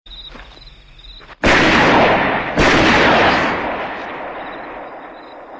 • GUNSHOTS IN THE FIELD.mp3
gunshots_in_the_field_vyh.wav